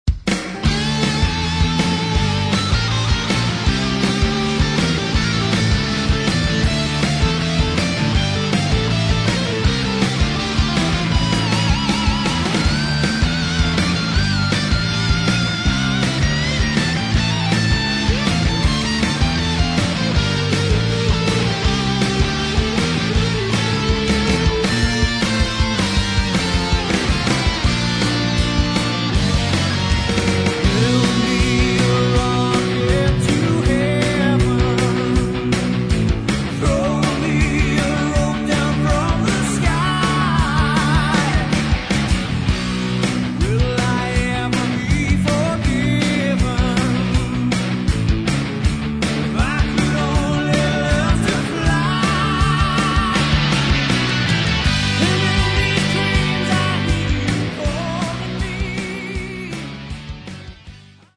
Metal
New Wave Of British Heavy Metal